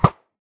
bowhit.ogg